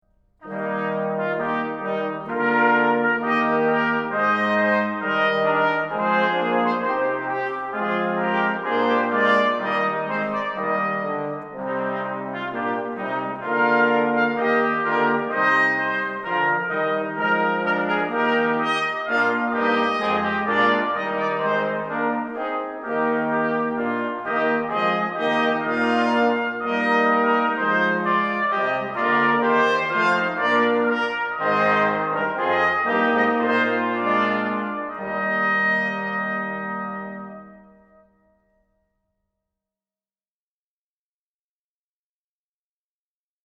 Warm, weit, strahlend – muss man selbst hören
Die Aufnahmen wurden von verschiedenen Ensembles aufgenommen und zur Verfügung gestellt.
Christian Schaefer, Solistische Besetzung der Badischen Posaunenarbeit, Gloria 2024 S. 17